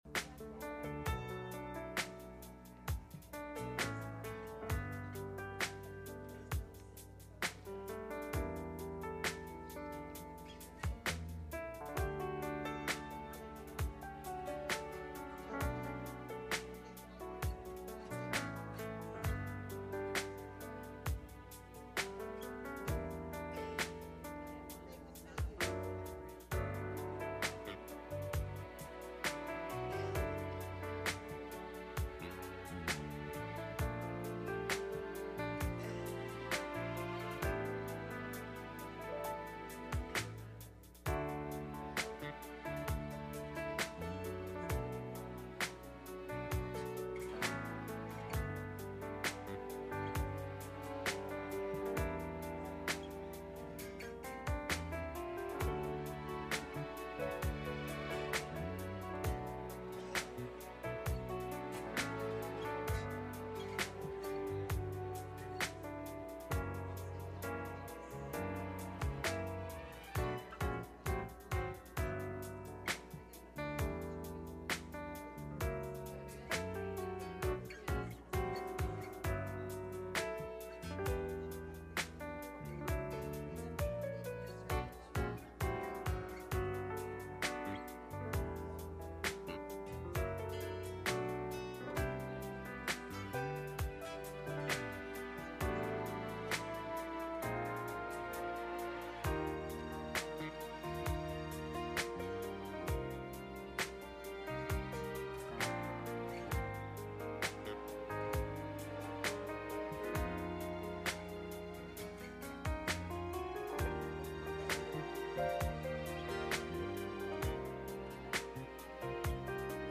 Wednesday Night Service
Service Type: Midweek Meeting